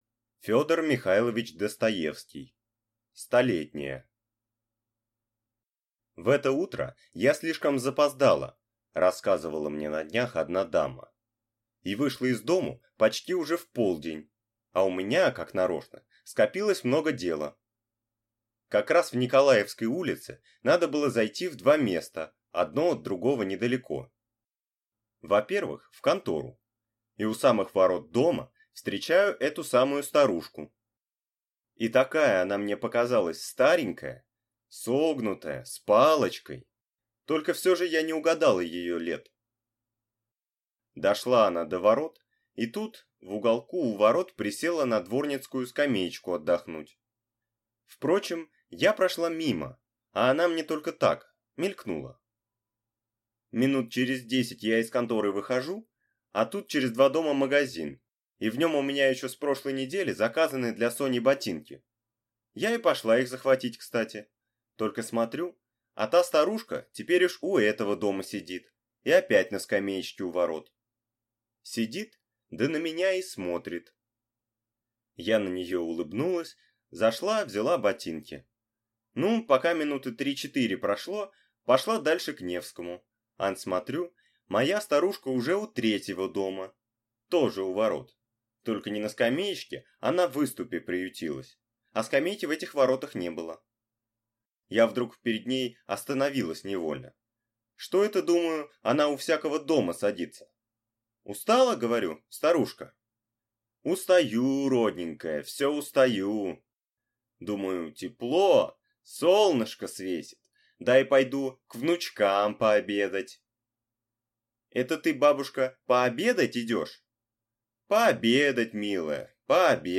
Аудиокнига Столетняя | Библиотека аудиокниг